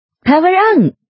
power_on.mp3